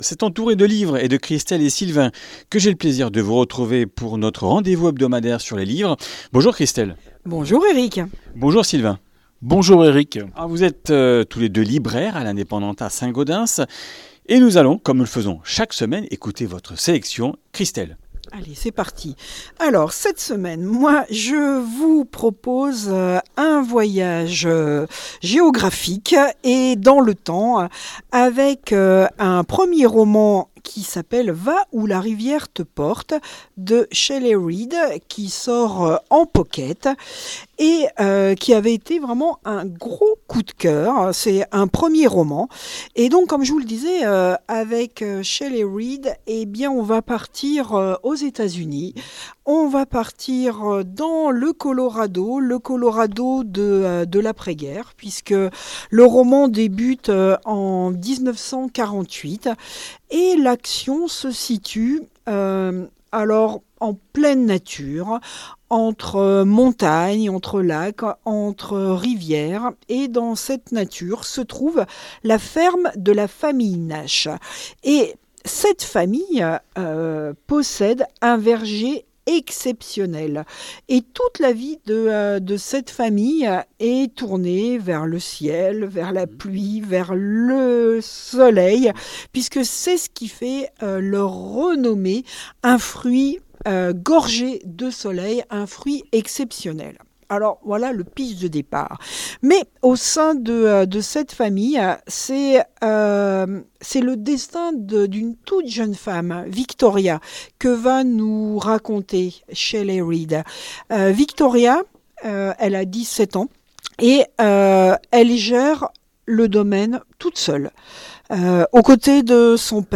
Comminges Interviews du 28 mars